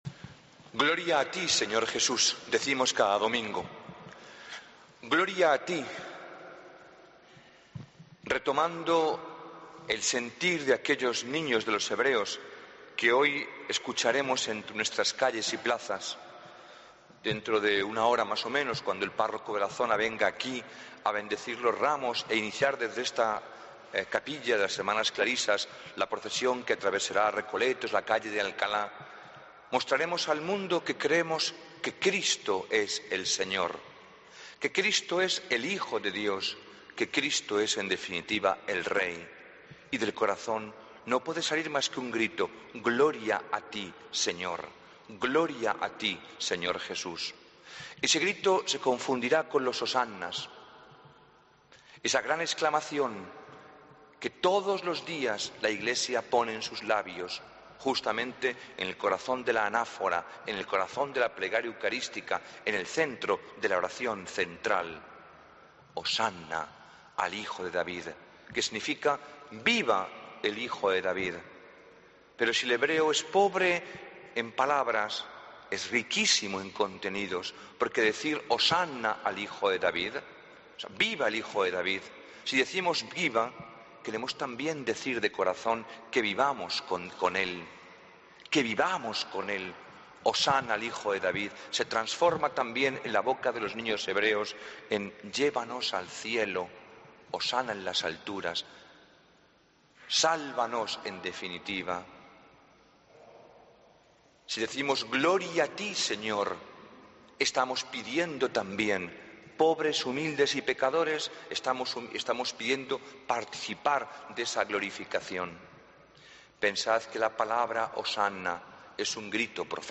Homilía del Domingo 13 de Abril de 2014